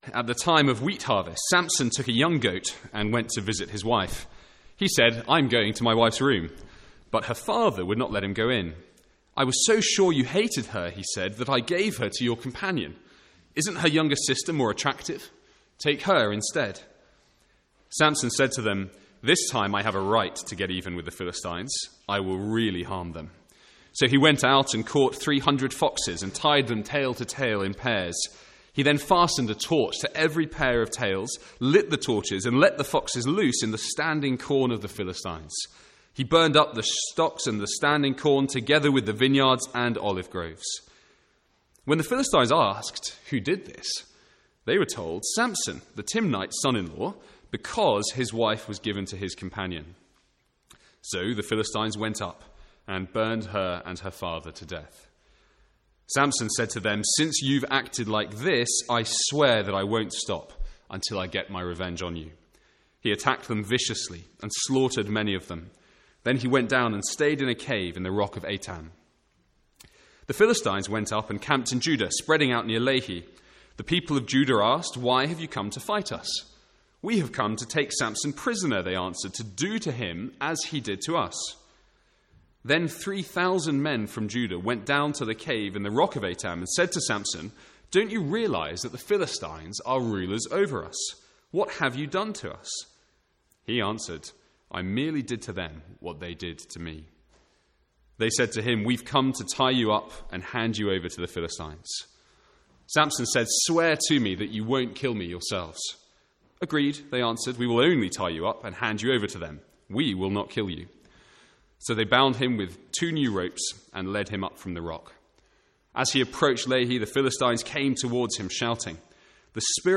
From the Sunday morning series in Judges.